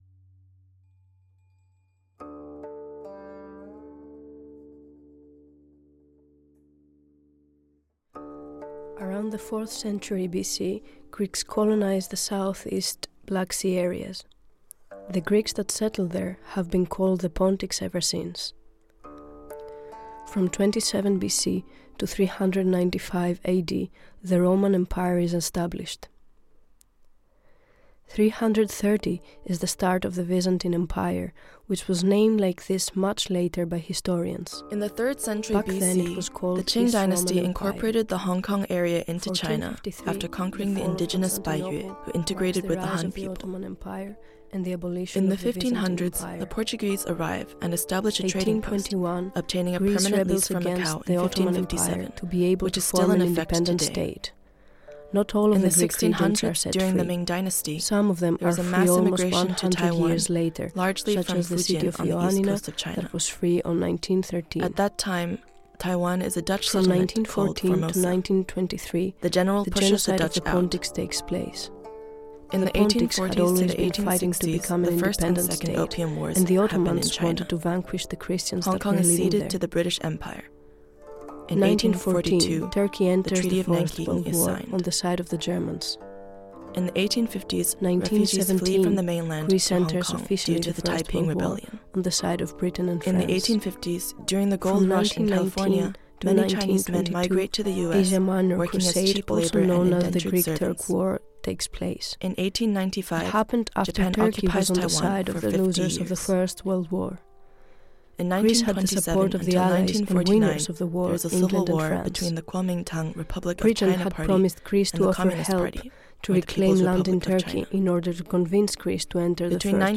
this collaborative, migrating sound piece wanders through the city, weaving together the micro and the macro, interspersing personal and familial narratives with historical and political context, connecting these histories by questioning current attitudes toward migration.